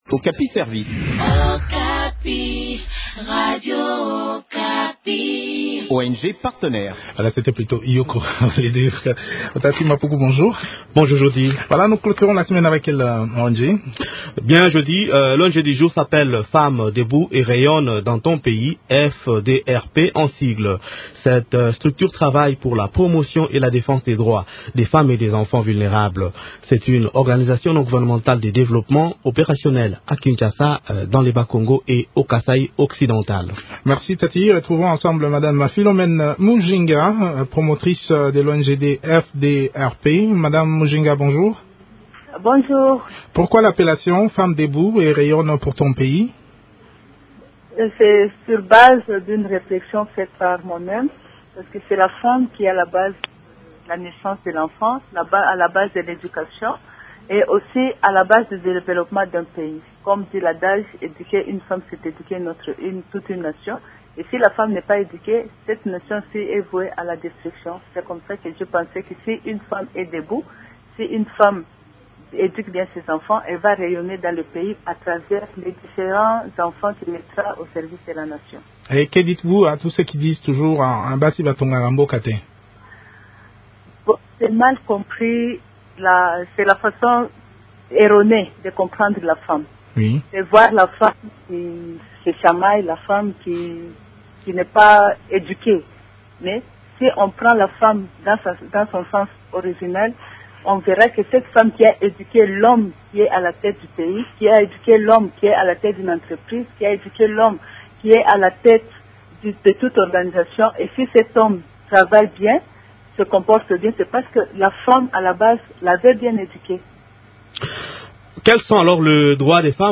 Les détails des activités de cette structure dans cette interview